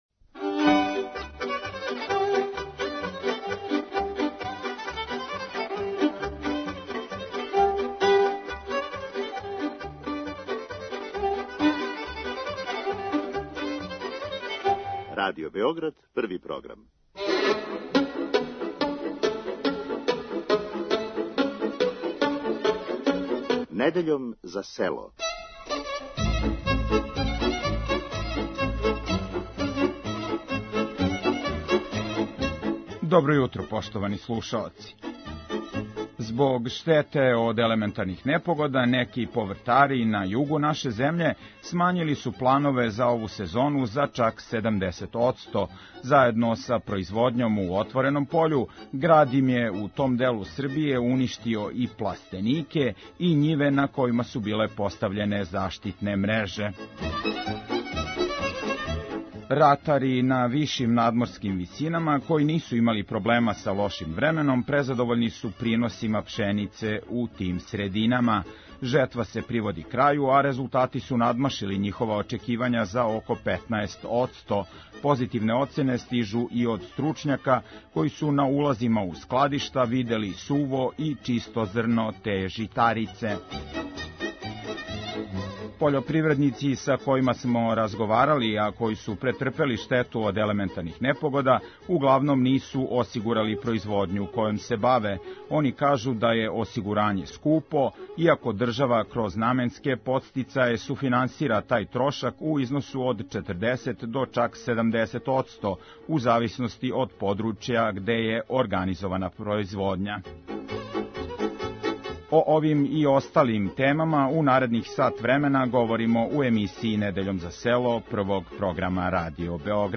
Уз остале теме из области пољопривреде у емисији Вас чека и традиционална народна музика из свих делова Србије.